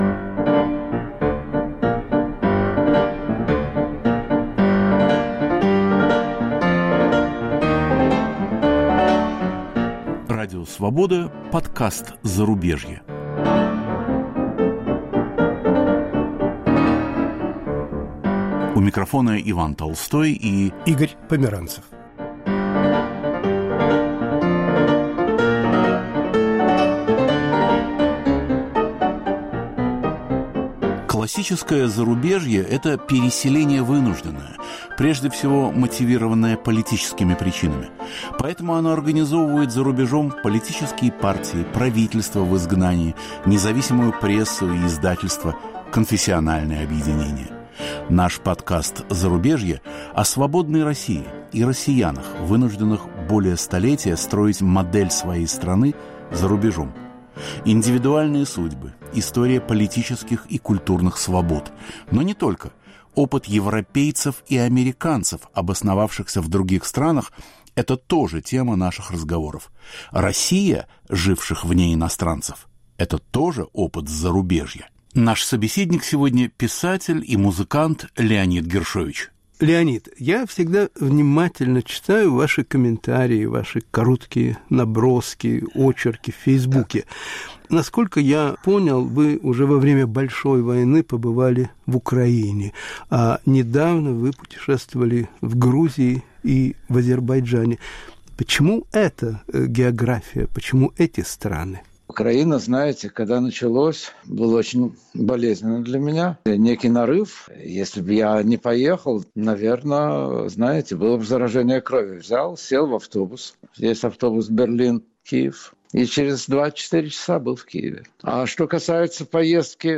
Жизнь на необитаемом острове. Интервью с Леонидом Гиршовичем
Гость нового выпуска подкаста "Зарубежье" – живущий в Германии писатель и музыкант Леонид Гиршович.